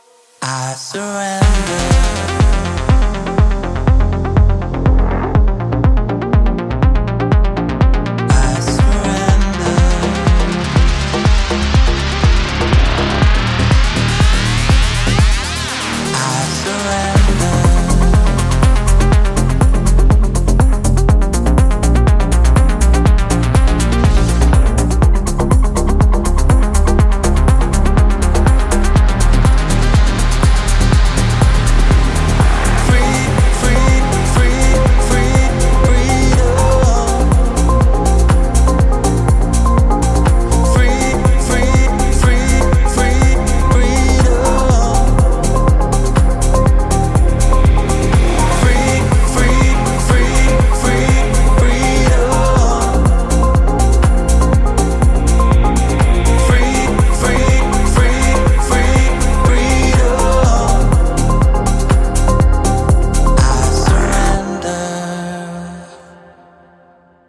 • Качество: 128, Stereo
электронная музыка
progressive house
клубная музыка
танцевальная музыка